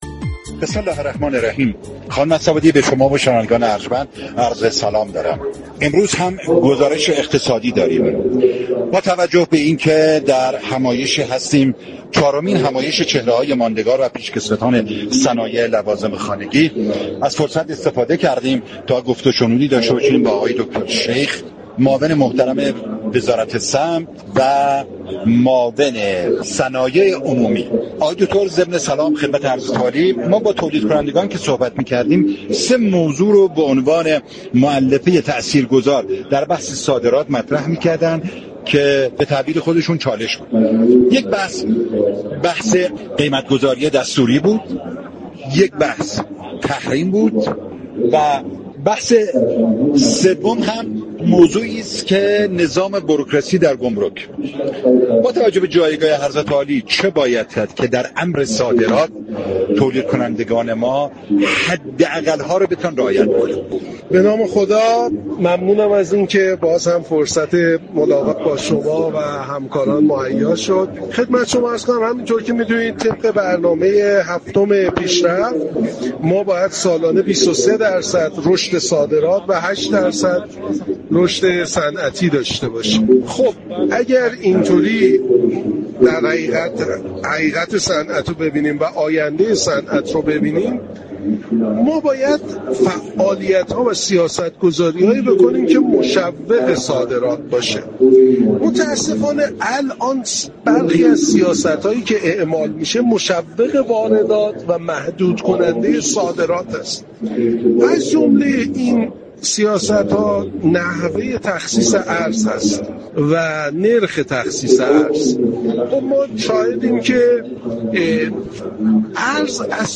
به گزارش پایگاه اطلاع رسانی رادیو تهران، ابراهیم شیخ معاون صنایع عمومی وزارت صمت در گفت و گو با «بازار تهران» اظهار داشت: طبق برنامه پنج ساله هفتم، باید شاهد رشد 23 درصدی صادرات و رشد هشت درصدی تولید باشیم.